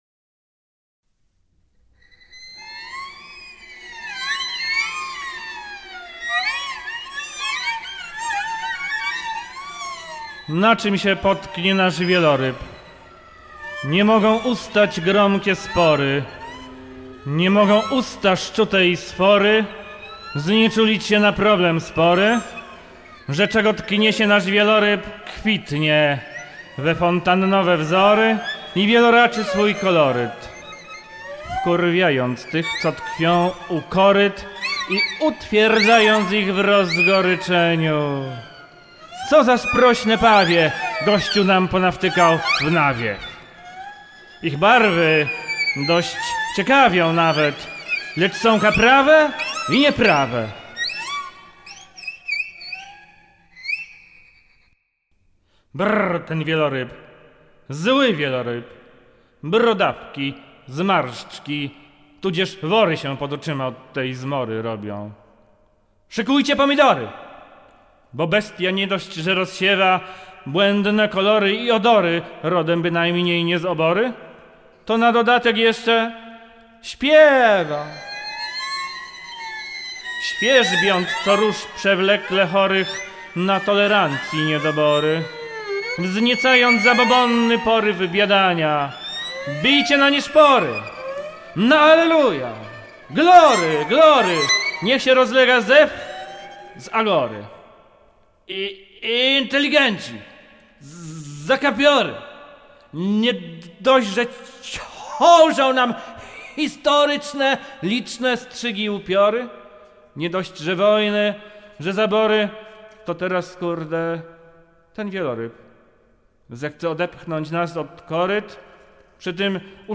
recytacje: